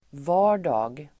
Ladda ner uttalet
Uttal: [v'a:r_da(:g)]